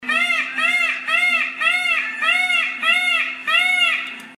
Звуки павлина